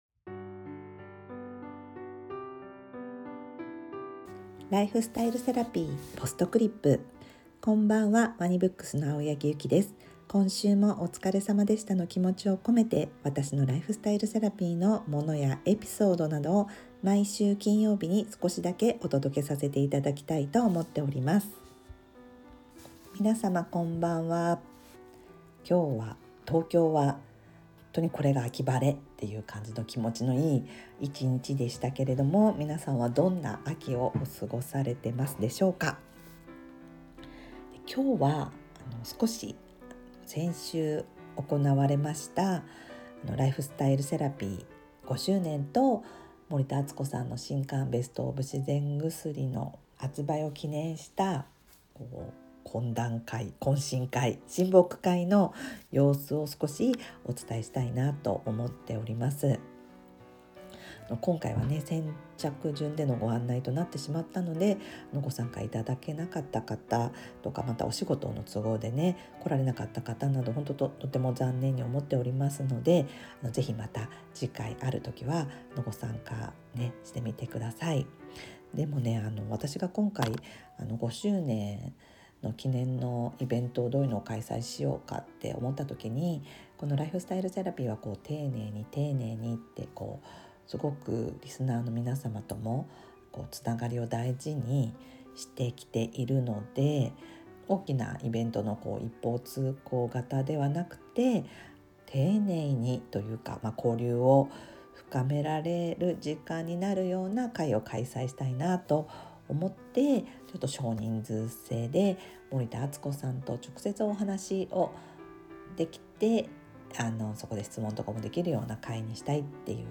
BGM／MusMus